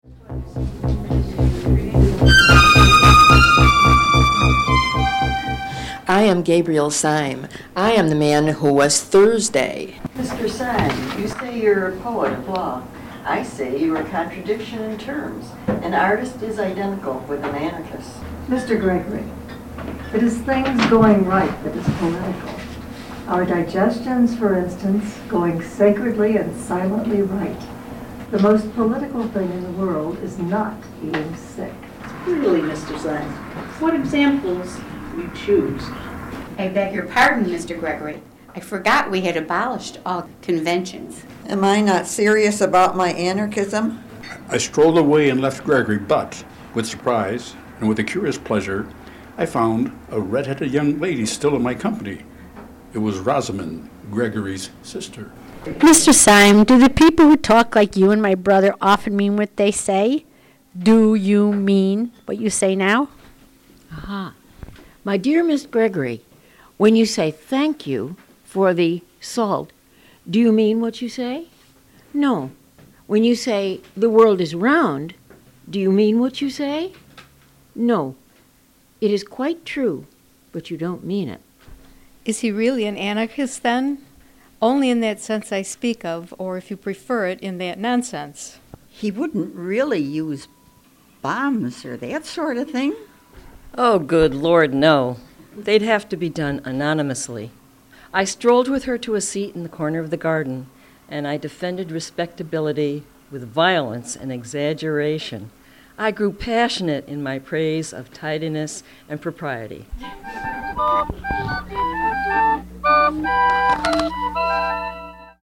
We drank some Art Beer, and then recorded an excerpt of the radio play “The Man Who Was Thursday,” originally produced by Orson Welle’s Mercury Theatre. Everyone was a great sport, both about being squished into our small space, and in doing a great reading.